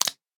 sounds / entity / fish / flop4.ogg
flop4.ogg